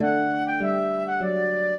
flute-harp
minuet0-3.wav